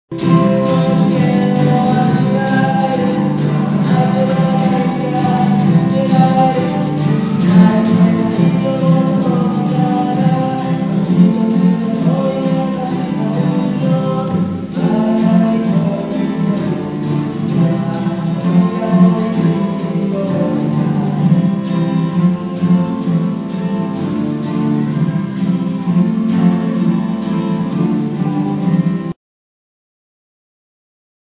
※これも某体育館で練習した時の音です。このときの録音は、マイクは使わず、機器を離して録ったものなのでなおさらぼやぼや聞こえるのです。さらにHPの容量が限られているので、節約のため音質をかなり下げてあります。なんて歌ってるかよく聞こえないね。